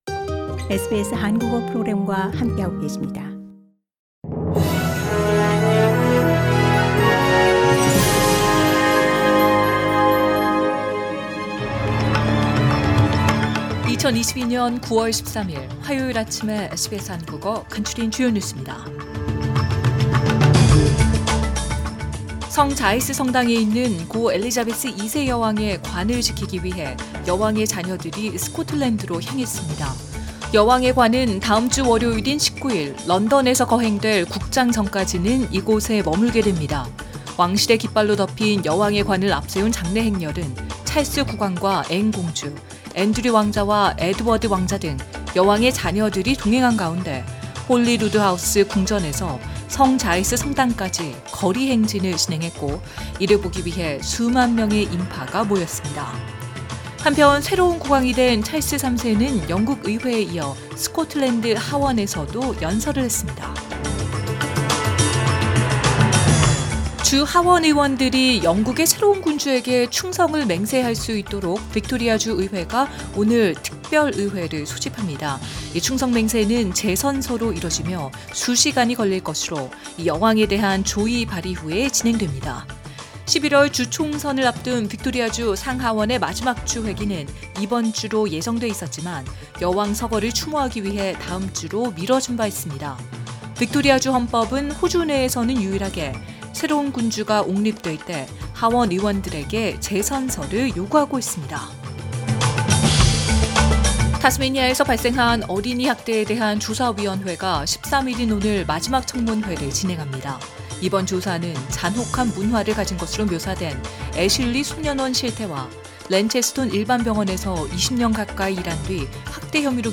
2022년 9월 13일 화요일 아침 SBS 한국어 간추린 주요 뉴스입니다.